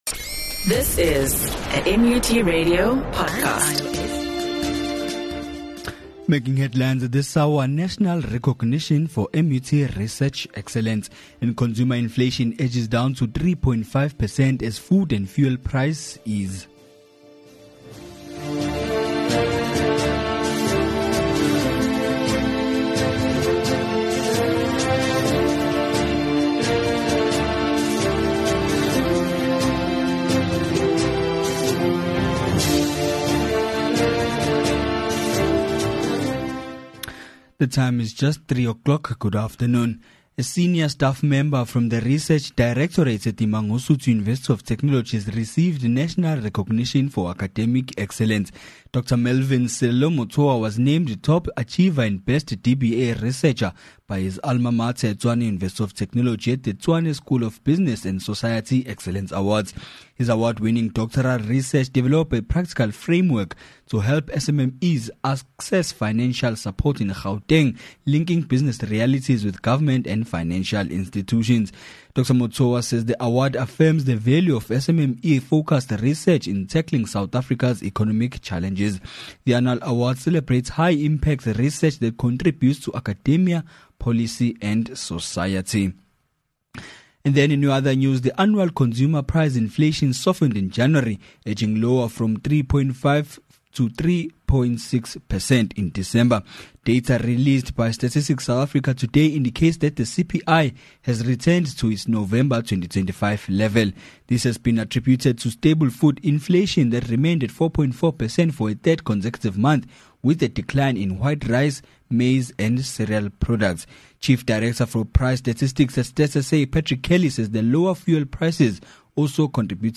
MUT RADIO NEWS AND SPORTS